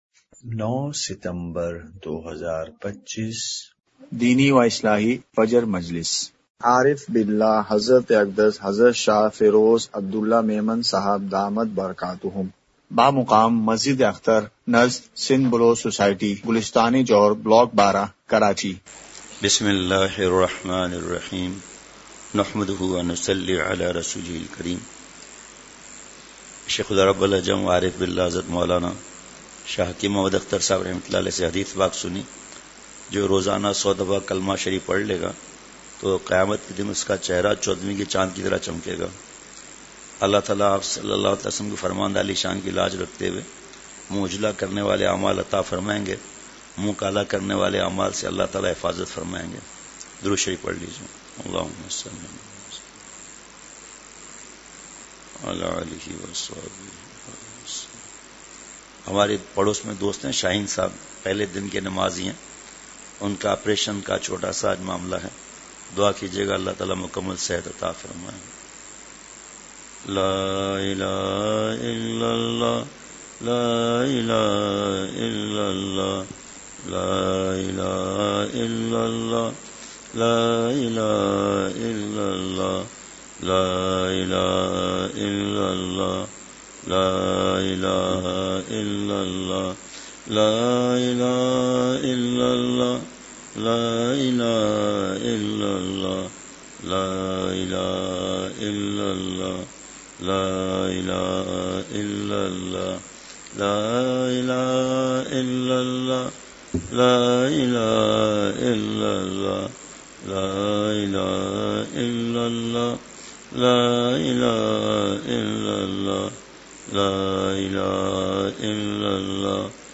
*مقام:مسجد اختر نزد سندھ بلوچ سوسائٹی گلستانِ جوہر کراچی*
مجلسِ ذکر:کلمہ شریف کی ایک تسبیح۔۔۔!!